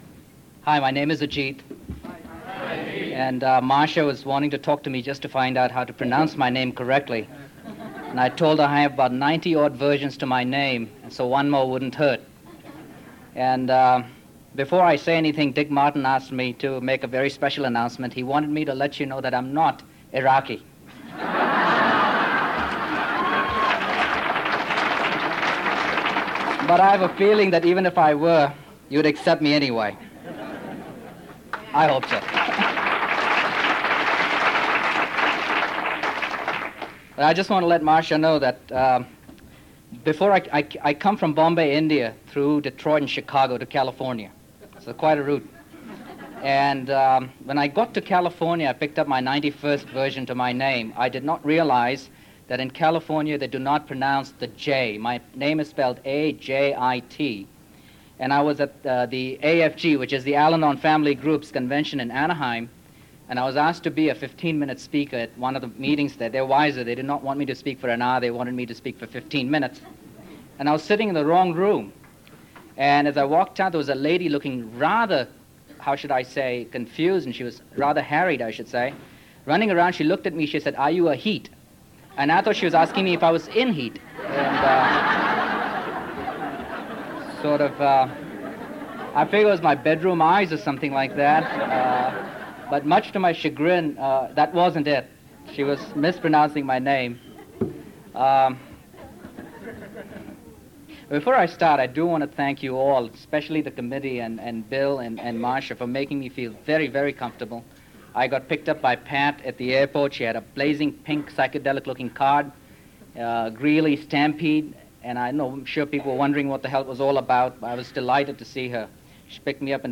40th Greeley Stampede &#8211